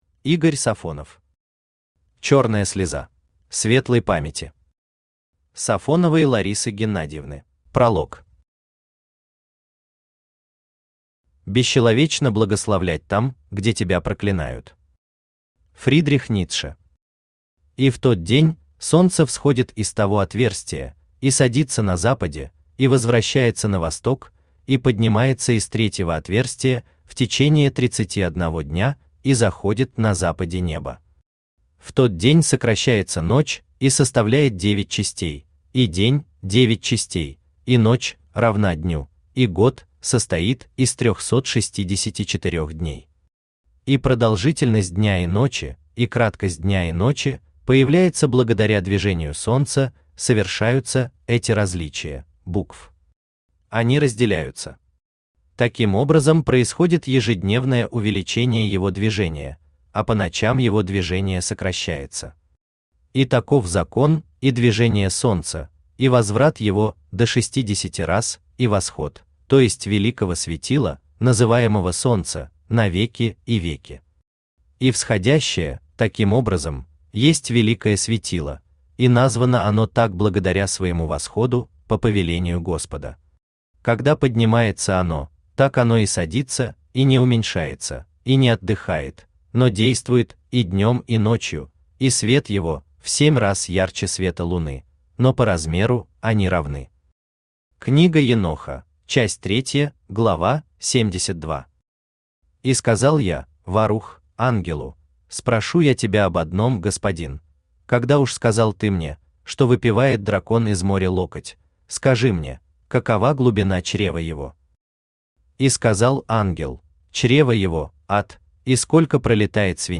Аудиокнига Черная слеза | Библиотека аудиокниг
Aудиокнига Черная слеза Автор Игорь Валерьевич Сафонов Читает аудиокнигу Авточтец ЛитРес.